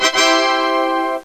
tada.wav